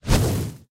mob / ghast / fireball4.ogg
fireball4.ogg